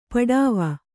♪ paḍāva